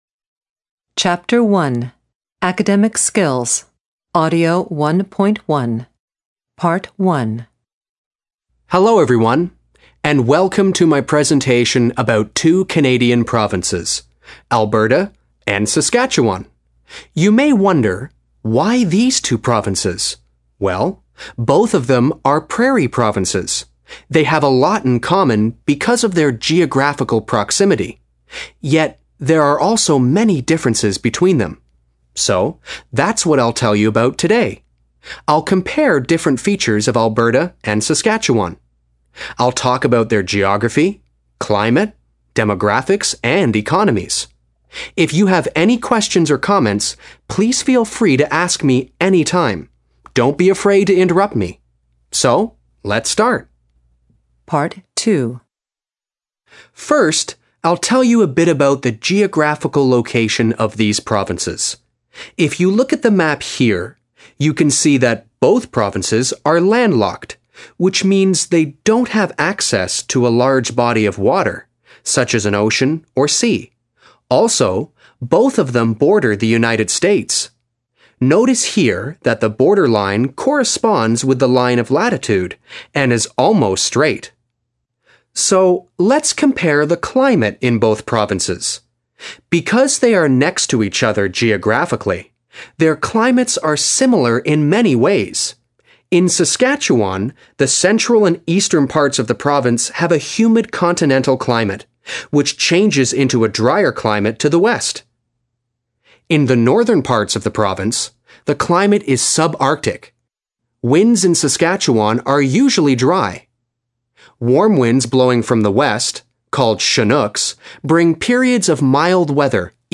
the introduction of a presentation